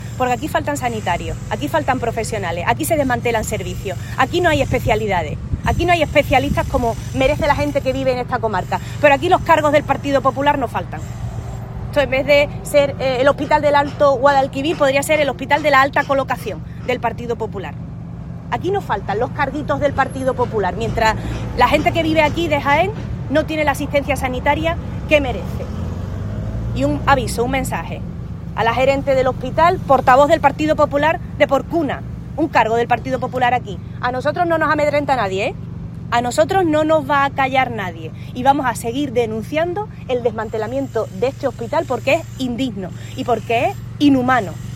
La responsable socialista hizo estas declaraciones a las puertas del Hospital Alto Guadalquivir de Andújar, que podría ser rebautizado como “Hospital de Alta Colocación del PP” por la cantidad de personas vinculadas a este partido que han encontrado puesto en este centro.